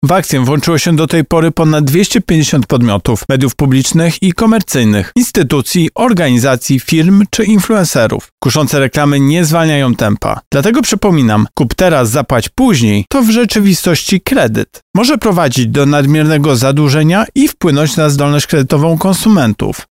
O szczegółach mówi Tomasz Chróstny, Prezes UOKiK: